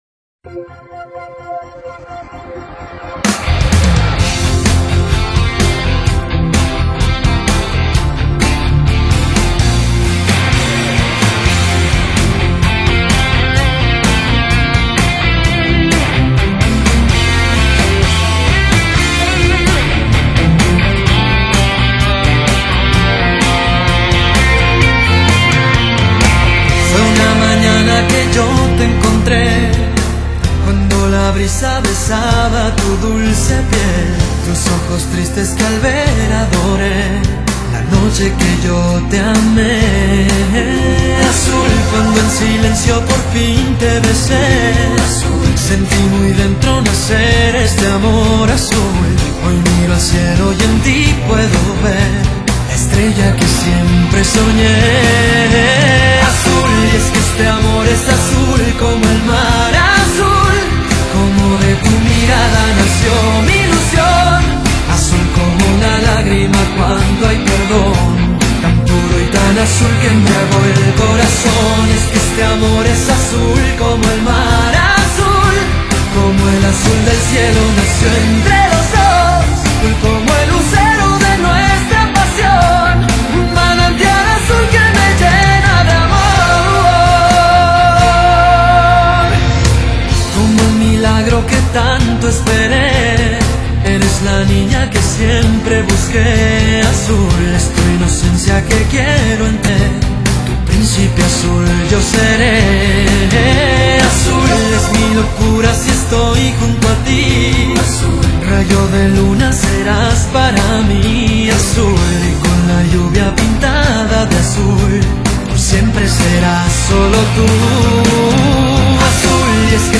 把热闹、动感的拉丁摇滚曲风演绎得酣畅淋漓
他磁性的嗓音不管表现忧郁抑或欣然都能信手拈来，使专辑呈现出舒缓的浪漫诗篇的景象。